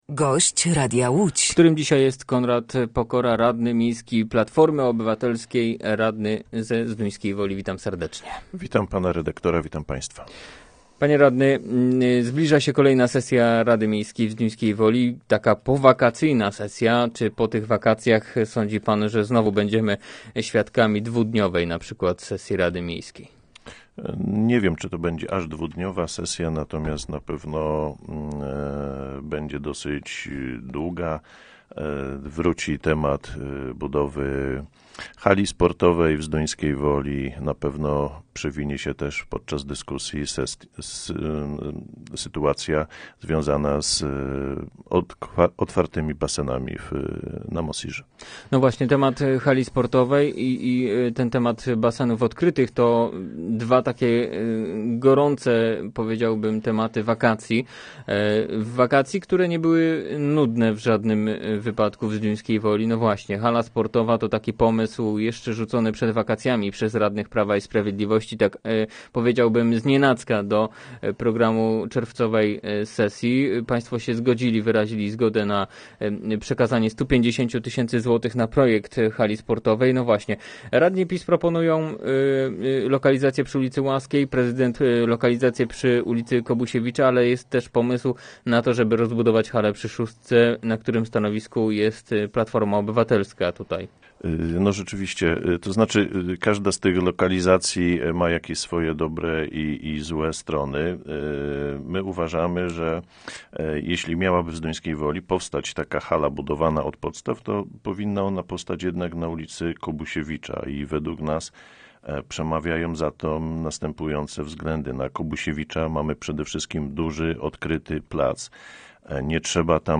Gościem Radia Łódź Nad Wartą był zduńskowolski radny miejski Platformy Obywatelskiej Konrad Pokora.